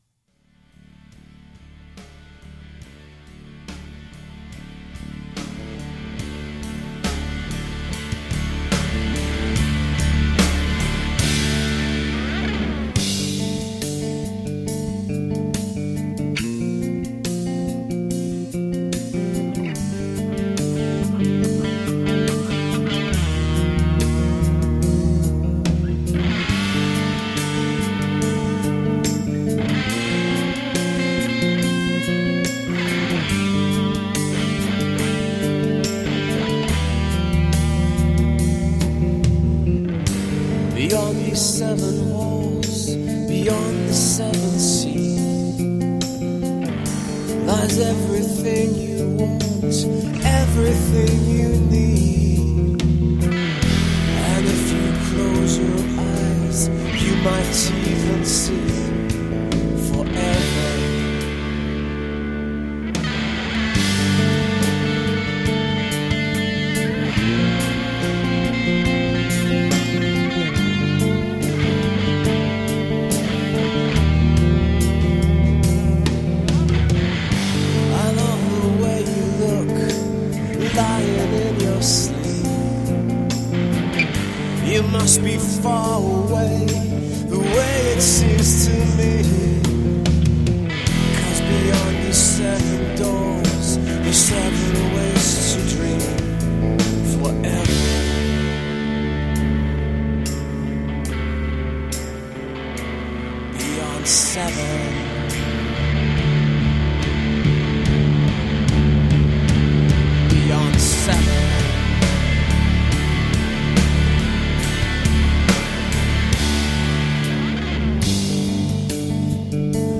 7 slices of raw guitar pop.
Tagged as: Alt Rock, Other